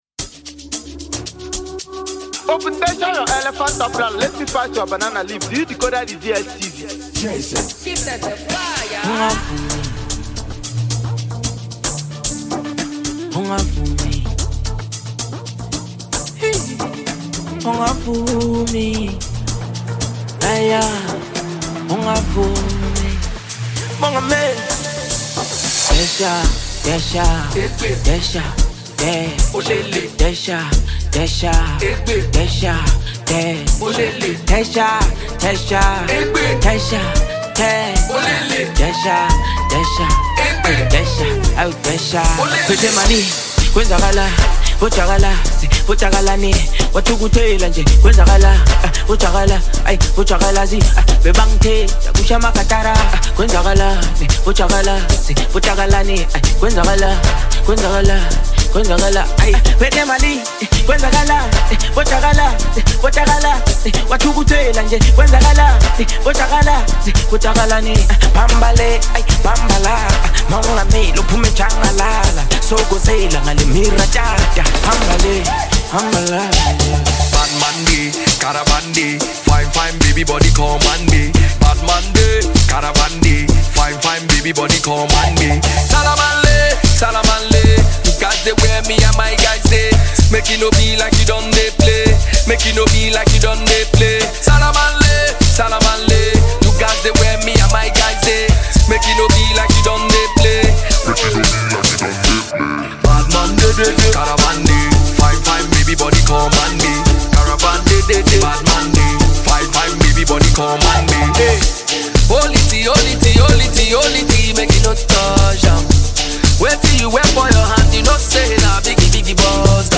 pulsating song